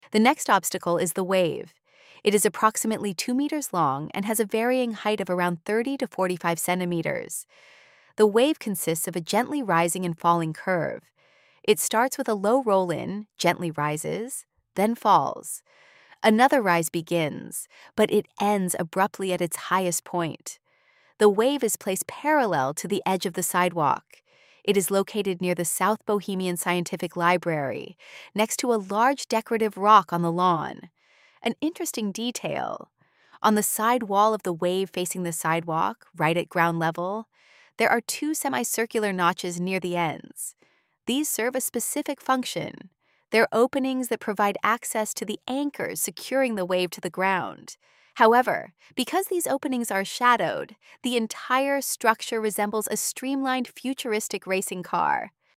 AUDIODESCRIPTION WAVY LEDGE